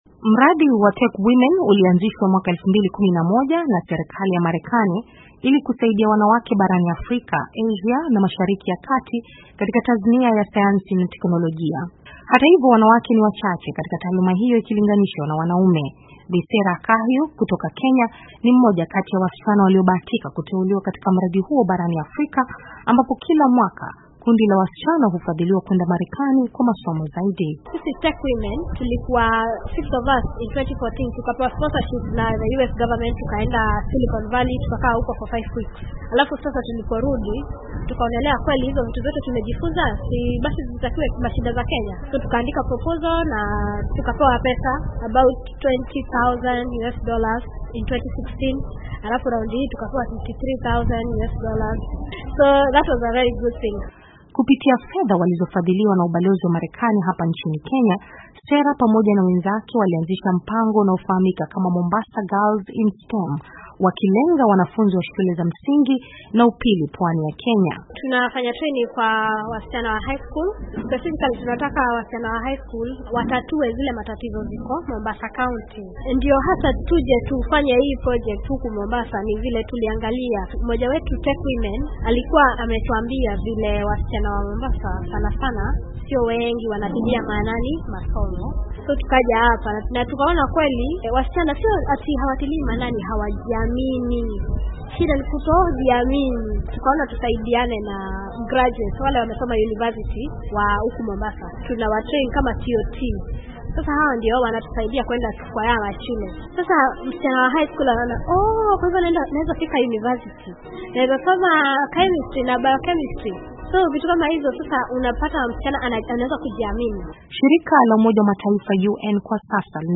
anaripoti zaidi kutoka Mombasa